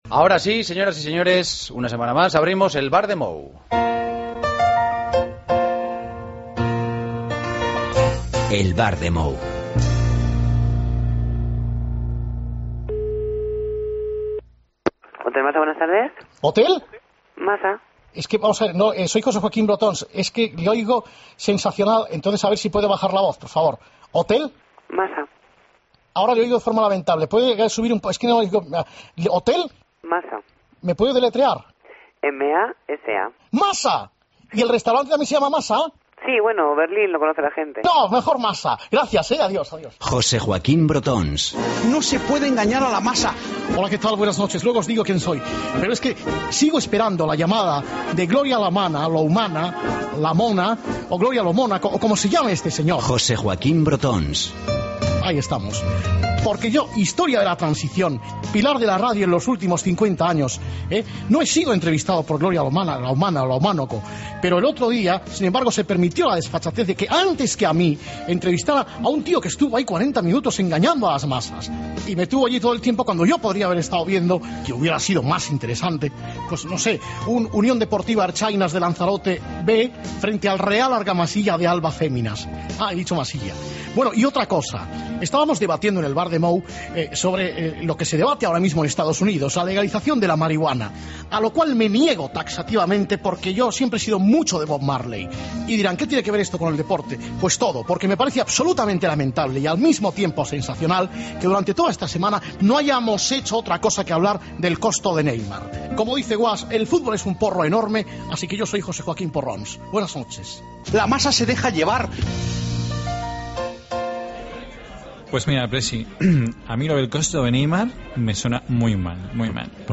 AUDIO: El Grupo RISA trae el humor a El Partido de las 12. El 'otro Cerezo' llama pregunta por vuelos entre Madrid y Vallecas.